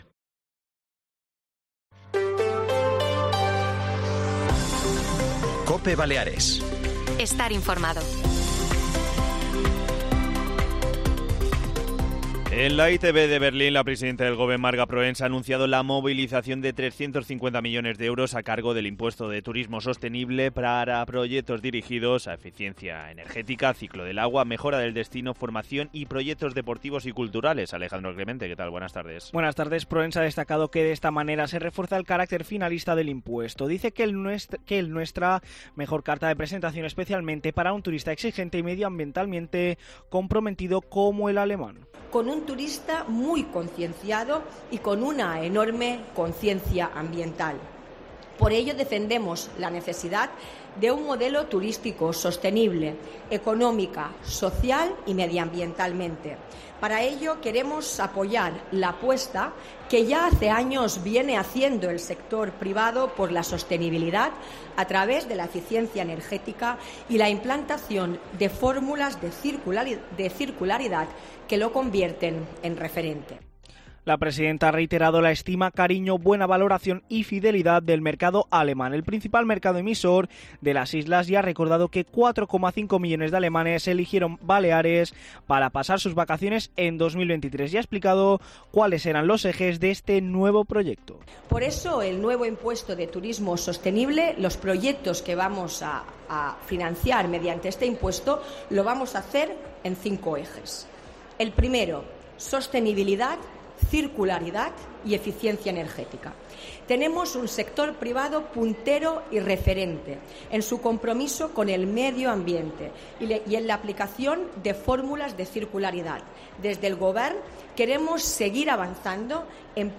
Cope Mallorca acude a la ITB de Berlín para entrevistar a las máximas autoridades insulares